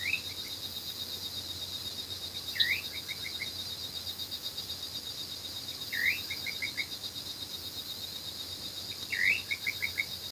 Atajacaminos Chico (Setopagis parvula)
Nombre en inglés: Little Nightjar
Localización detallada: Villa Paranacito, Area Protegida Arroyo Las Palmas
Condición: Silvestre
Certeza: Vocalización Grabada